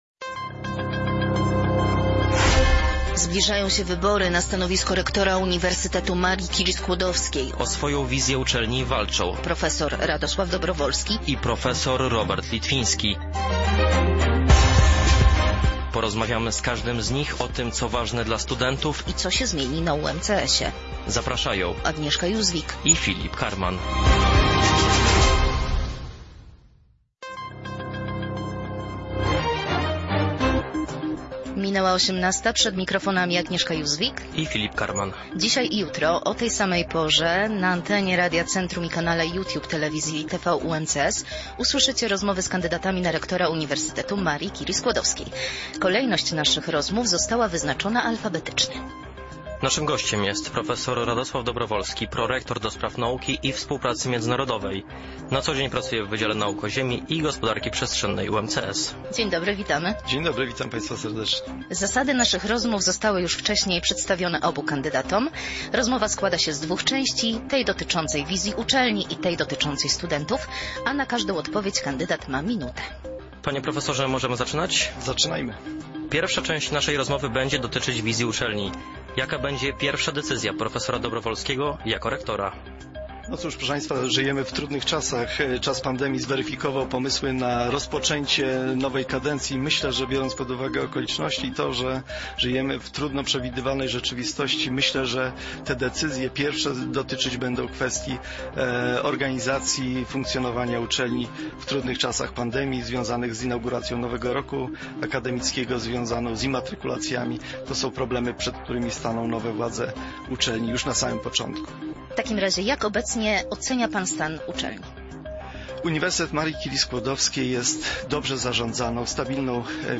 Obaj kandydaci odpowiedzieli na te same pytania, a kolejność naszych rozmów została wyznaczona alfabetycznie.